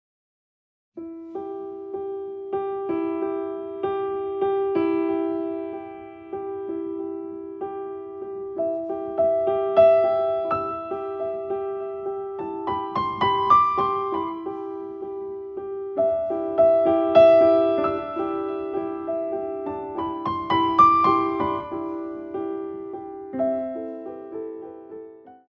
Ballet class music for advanced dancers
4x8 - 6/8